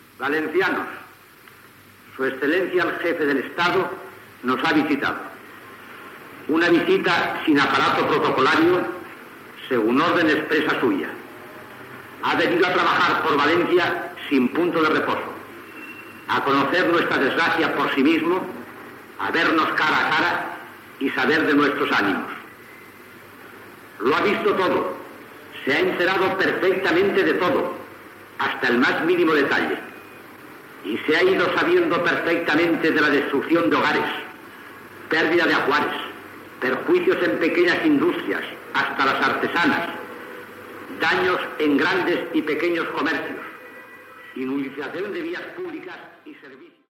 El capità general de València Joaquín Ríos Capapé s'adreça a la població valenciana dies després de les riuada del Túria (14 d'octubre de 1957).
Paraules de l'alacalde de València Tomás Trénor marqués del Túria en el dia que el cap d'Estat Francisco Franco visita València, el 25 d'octubre de 1957, dies després de les riuada del Túria.
Informatiu